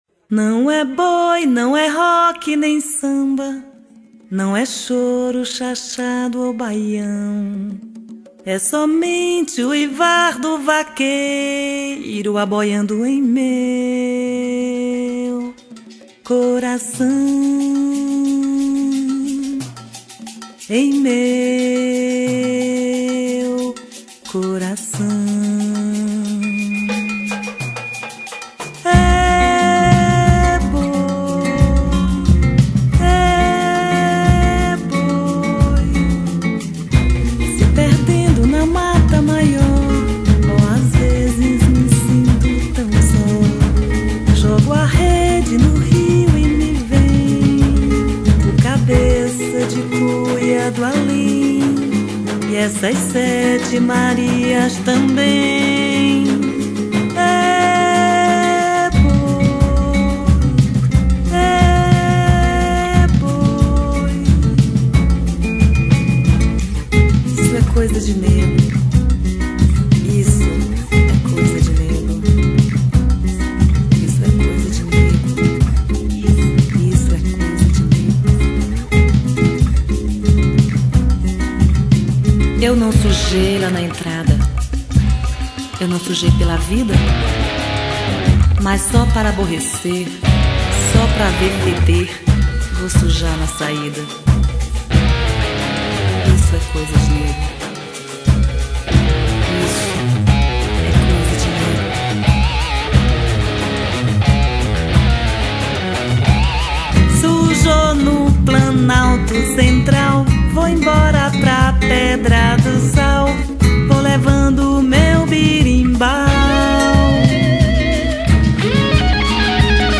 04:28:00   Boi Bumbá